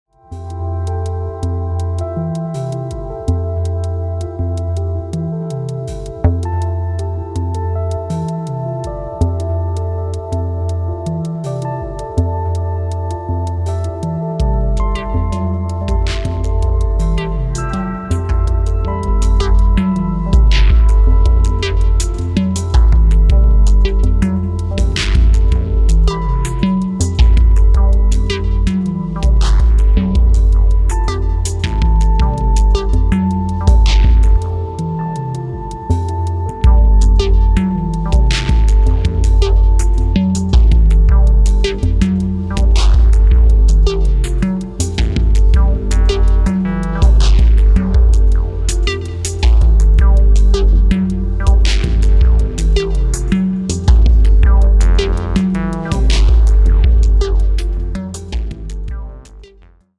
すこぶるディープで内省的なトライバル/アンビエント/クロスオーヴァーなグルーヴ。